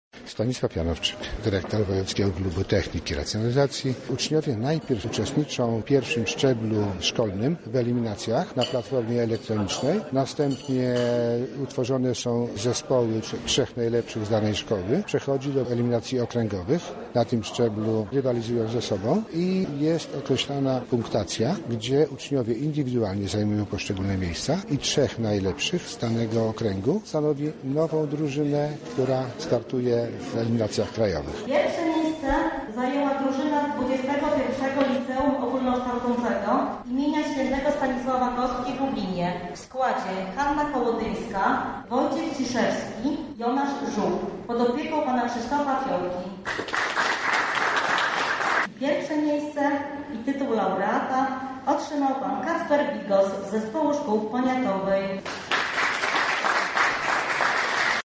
Podsumowanie eliminacji odbyło się dzisiaj w XXI Liceum Ogólnokształcącym w Lublinie. Na miejscu była nasza reporterka: